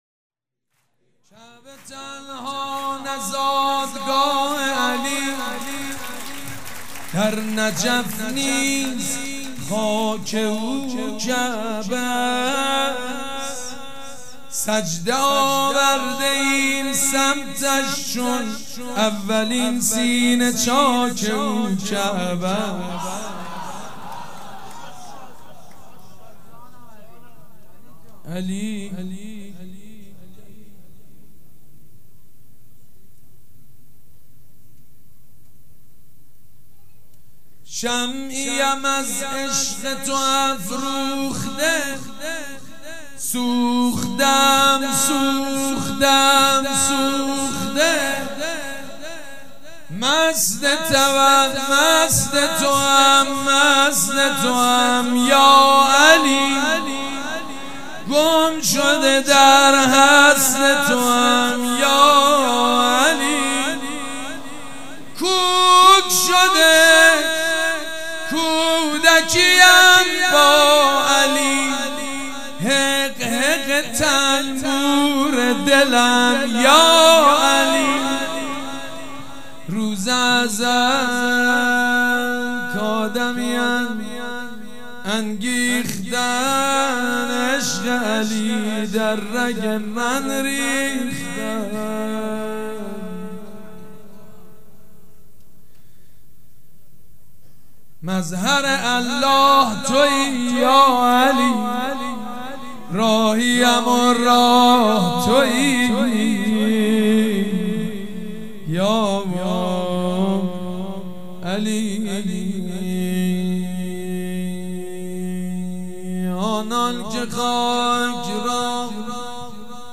مراسم میلاد پیامبر خاتم(ص)و حضرت امام جعفر صادق(ع)
شعر خوانی
مداح